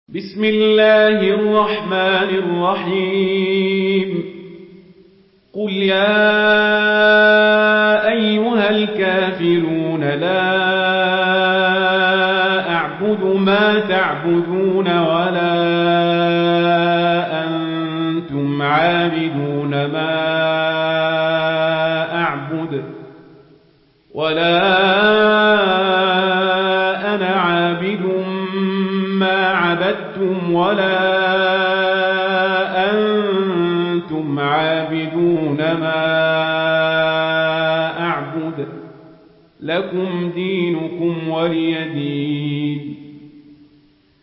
سورة الكافرون MP3 بصوت عمر القزابري برواية ورش
مرتل ورش عن نافع